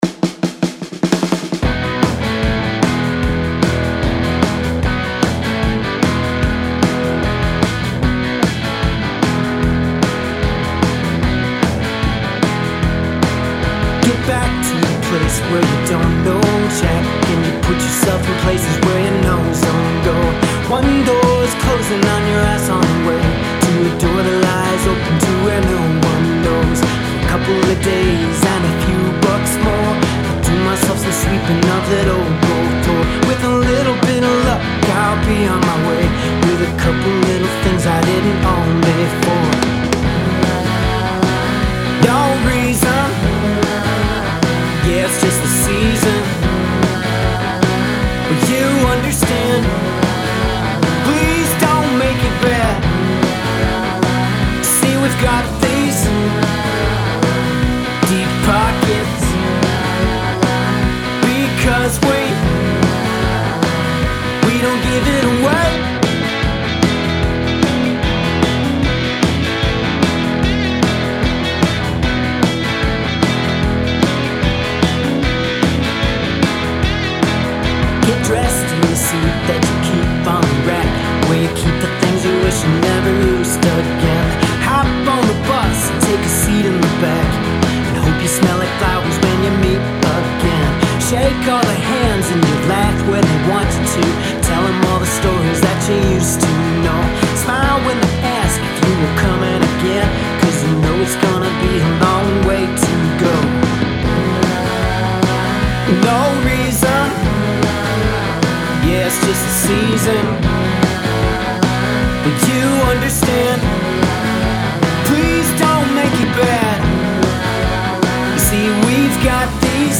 Okay I think I'm starting to get that warm punch I was looking for. Maybe overdid it a little but hell I love a fat drum n bass.
I brought the second guitar back, I like it to a fault maybe. I think the lifeless sound is from the amp sims anyway so it could probably use that extra excitement.